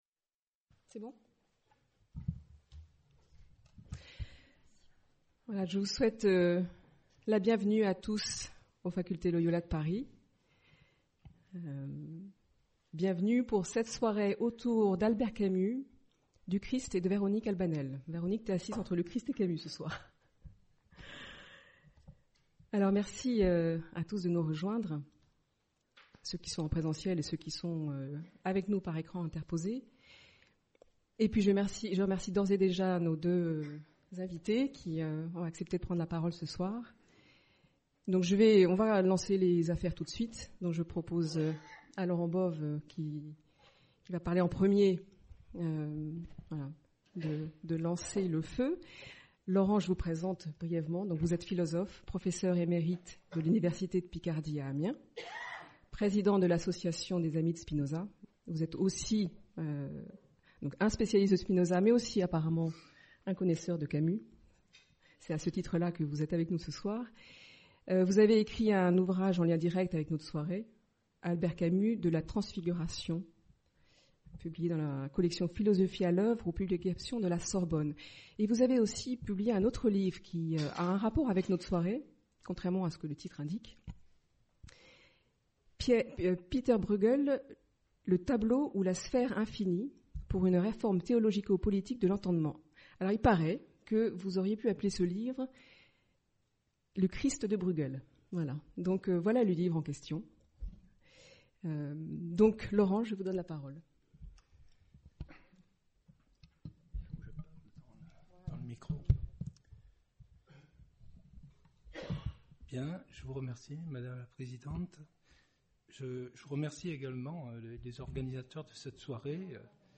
Soirée débat